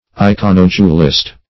Search Result for " iconodulist" : The Collaborative International Dictionary of English v.0.48: Iconodule \I*con"o*dule\, Iconodulist \I*con"o*du`list\, n. [Gr. e'ikw`n an image + ? a slave.]